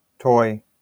IPA/tɔɪ/
wymowa amerykańska?/i